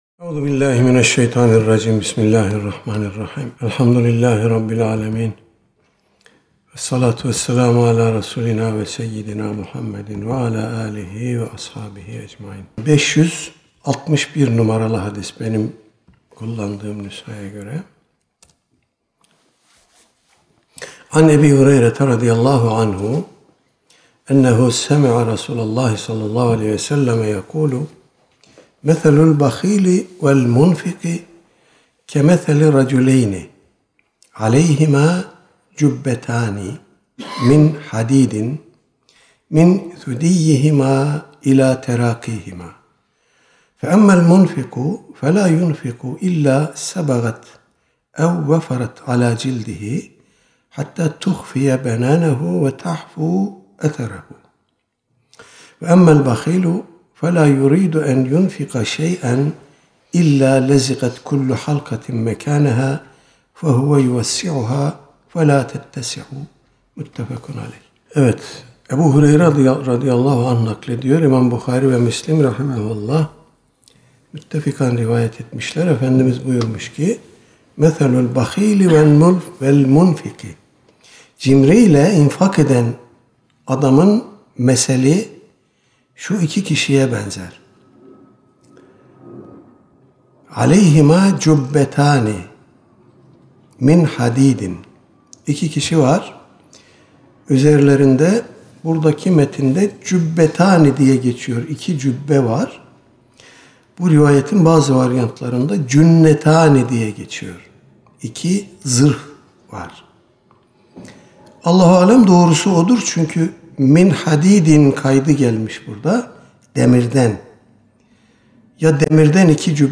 Her hafta mutat olarak icra edilen, Riyâzu’s-Sâlihin seminerimizde bu hafta, “Cömertlik ve İnfak” konusu ele alındı.